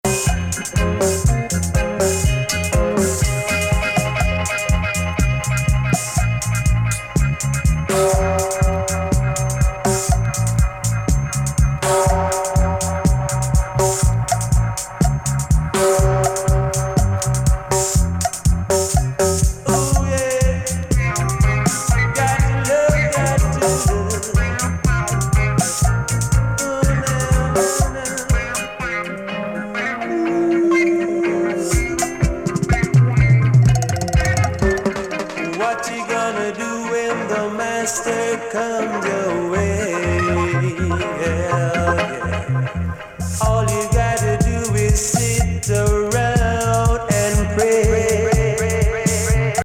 深々と深海を揺らめくような
DUB MIXを施した傑作!